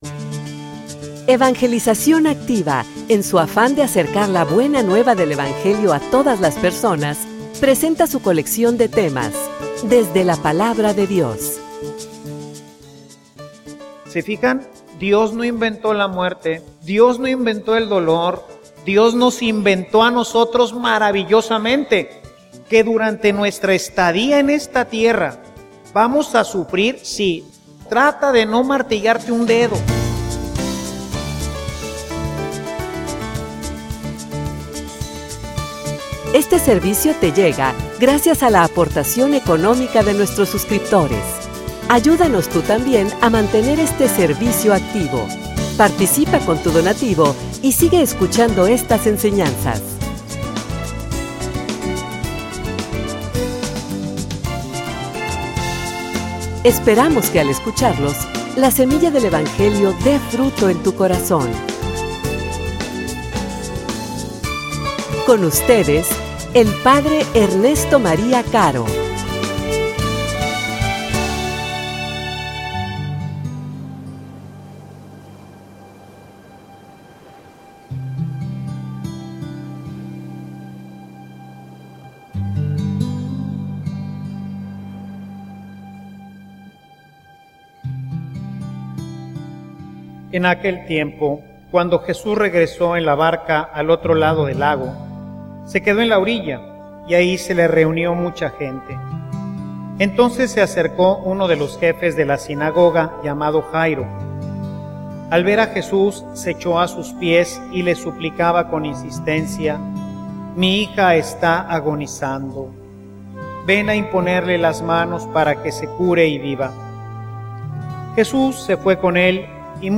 homilia_Que_te_conozca_que_me_conozca.mp3